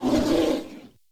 PixelPerfectionCE/assets/minecraft/sounds/mob/polarbear/warning2.ogg at mc116